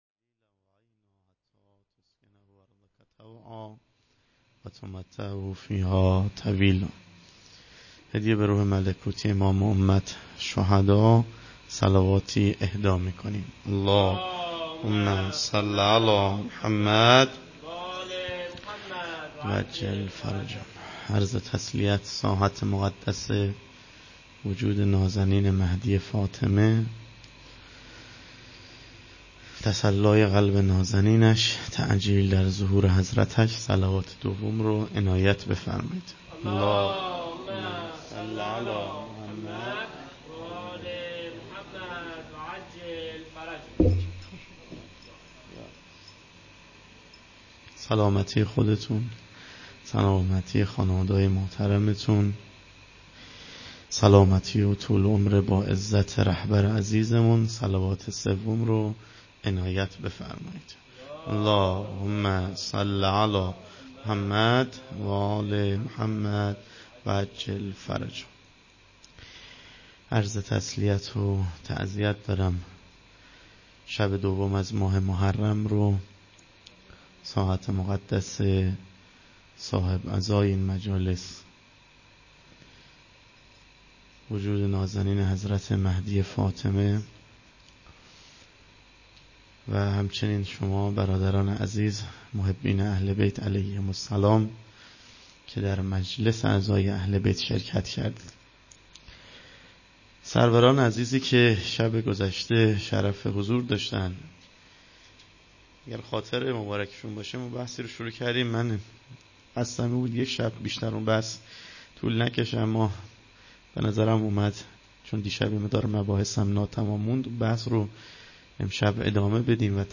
هیات انصارالمهدی (عج) بندرامام خمینی (ره)